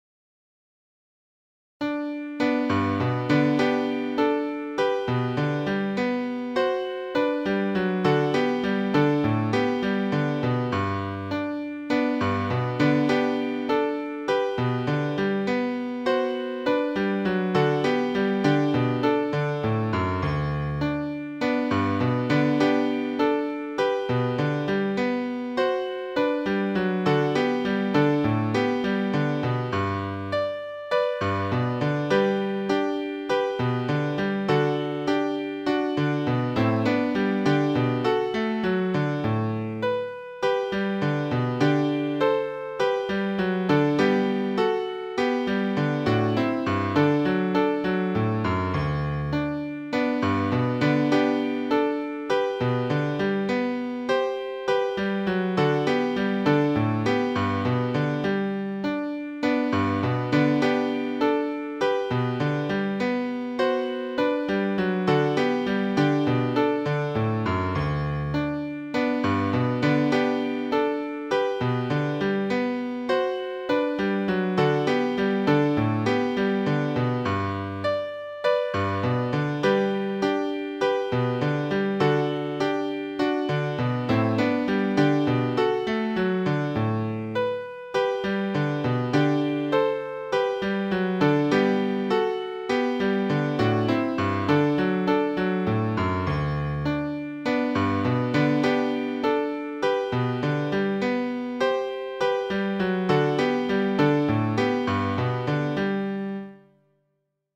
校歌
昆布森中学校校歌（ピアノ伴奏）.mp3